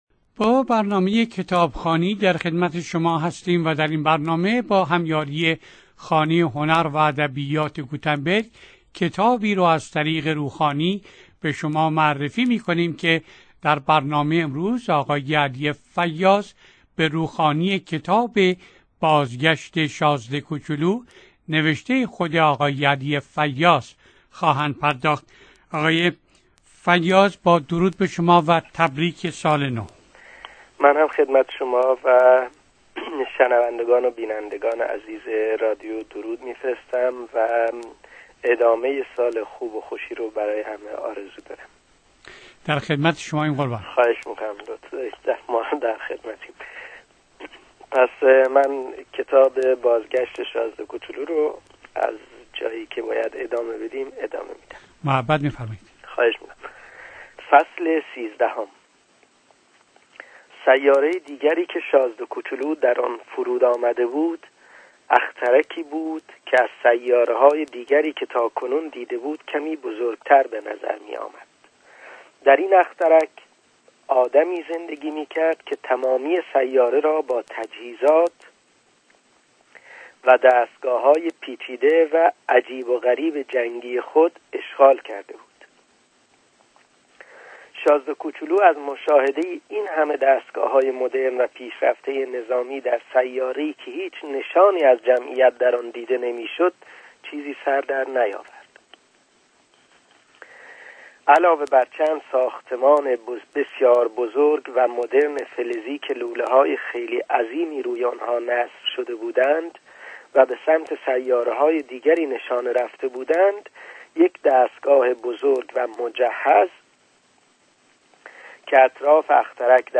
با هم این کتاب گویا را می شنویم